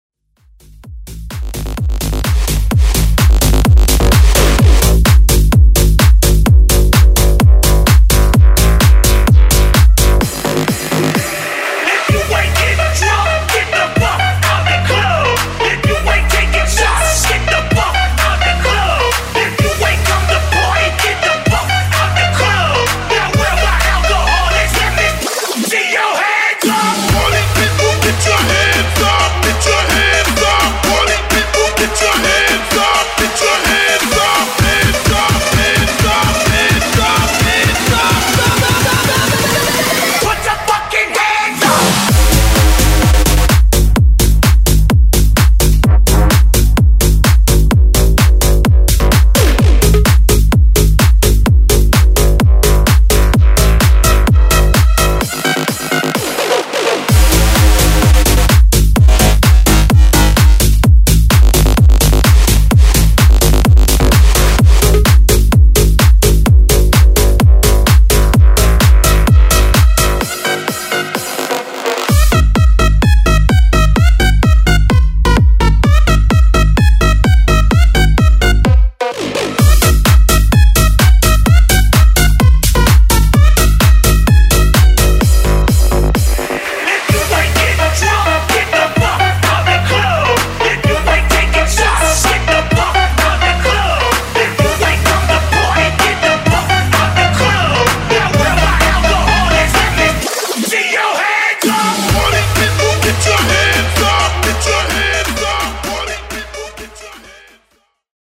BASS HOUSE , MASHUPS Version: Dirty BPM: 128 Time